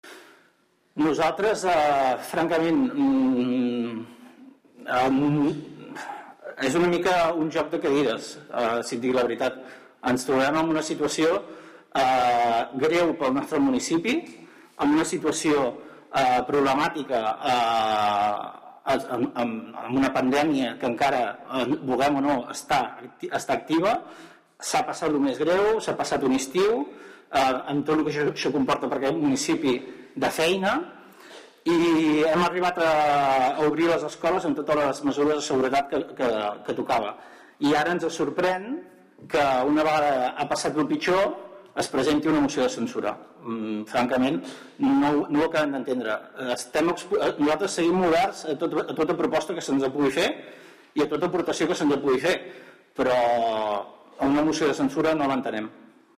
Vídeo de la Roda de Premsa de l’encara Alcalde, Miquel Bell-Lloch